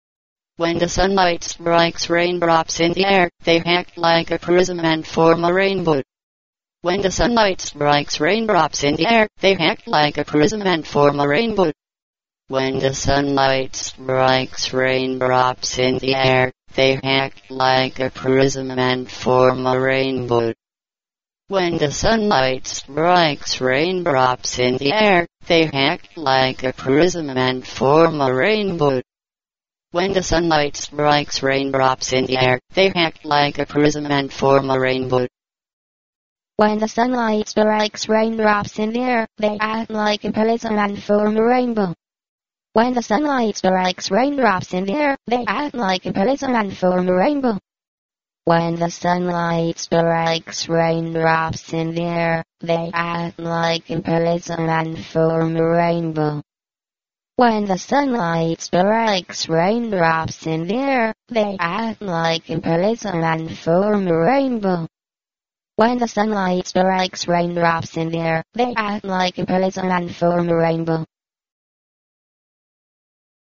85. Female and child voices with the same emotional states. (experimental diphone inventory). 1.12